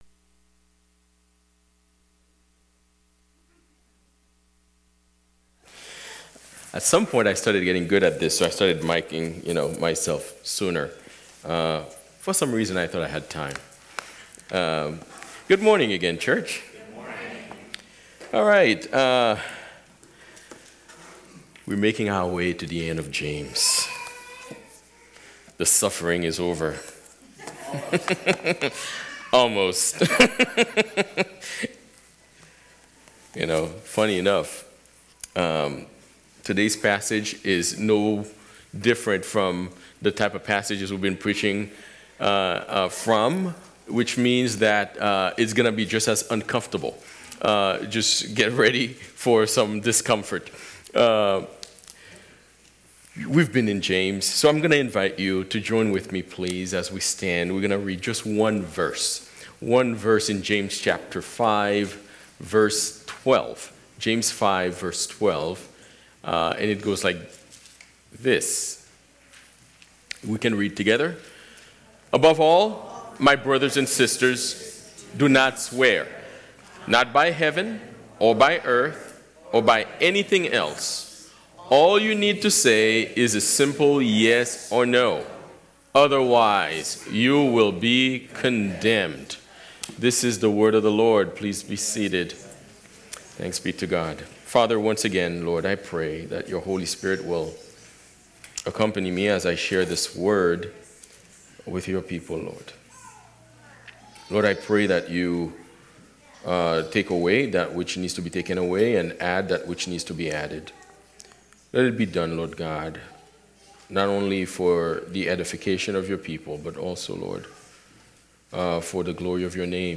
Sermons by Fellowship Church Dedham